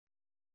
♪ tippuł